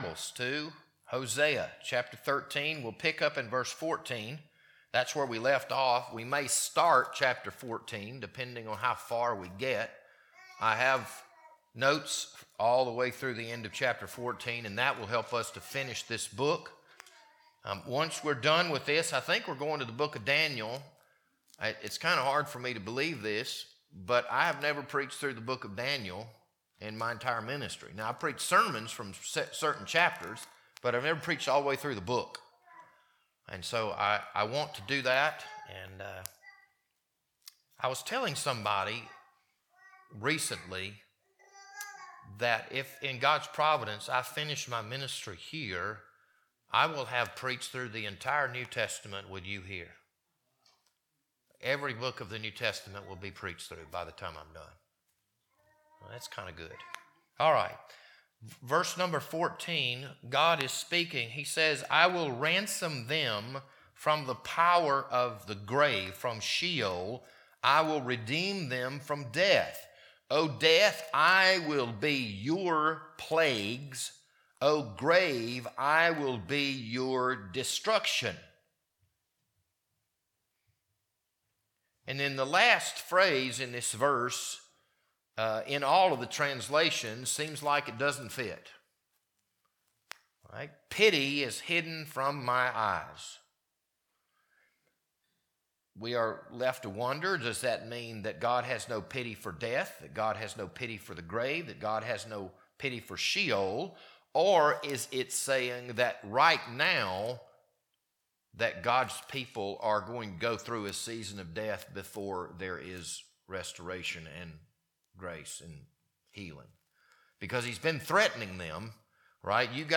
This Sunday evening sermon was recorded on March 15th, 2026.